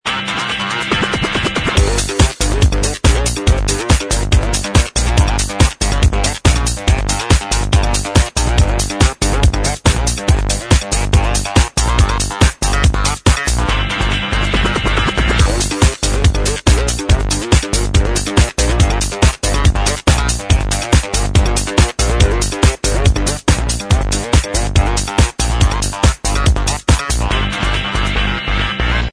In this remix i hear guitar's sounds.